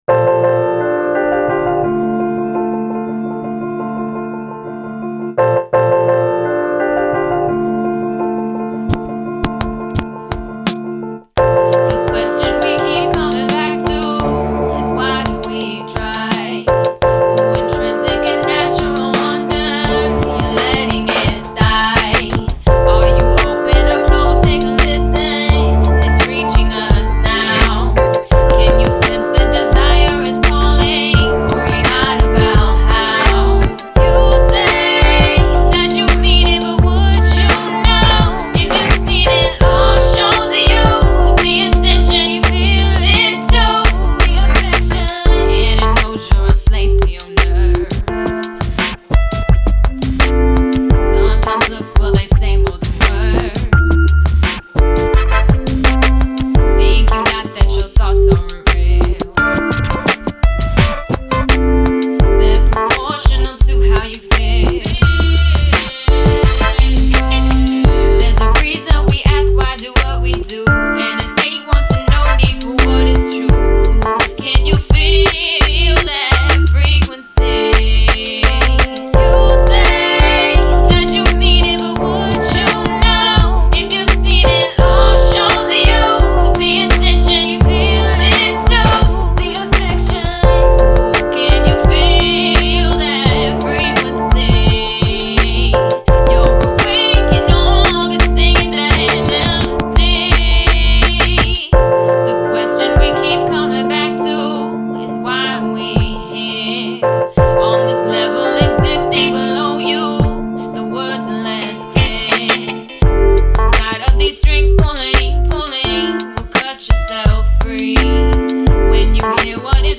Recorded at Ground Zero Studios